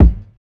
TS Kick_1.wav